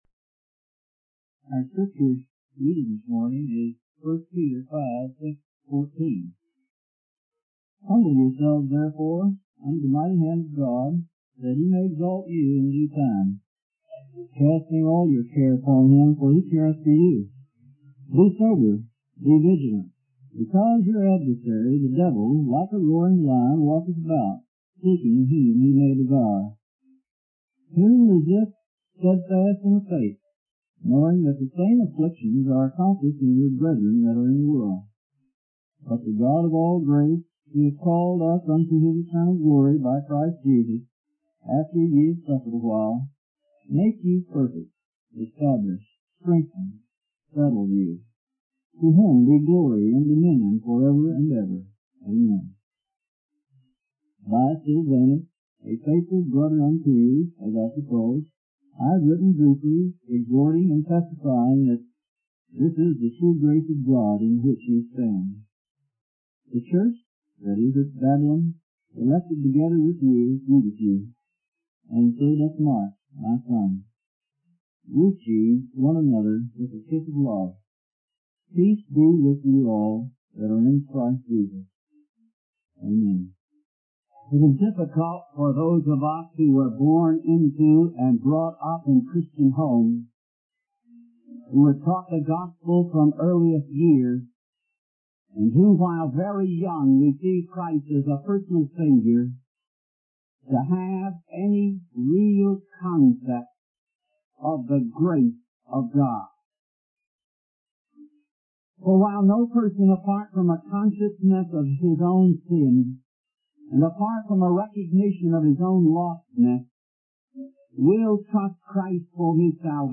In this sermon, the speaker begins by asking how we can shout to God and exalt Him in His time. He then discusses the importance of being vigilant because the devil is always seeking to harm us. The speaker emphasizes that our call to God's eternal glory comes through the work of Jesus Christ, who has provided cleansing for our sins.